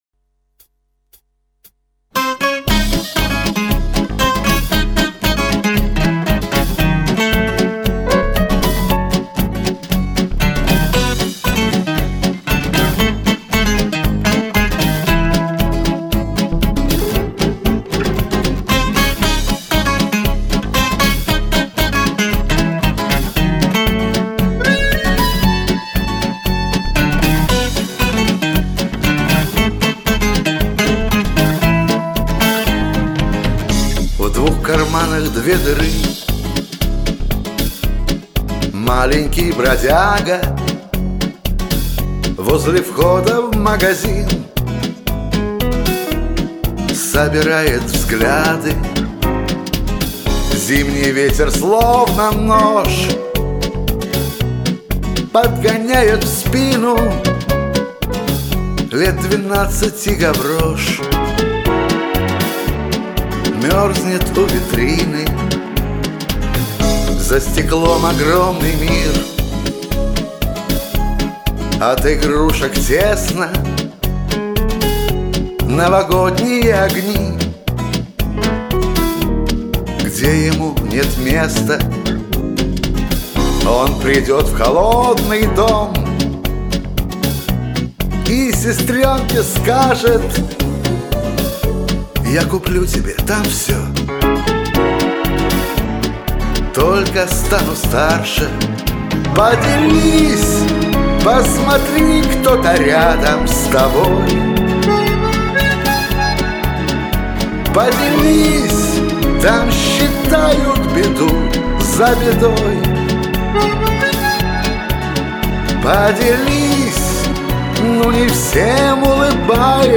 Песни такие серьёзные на простой шансоновый ритм.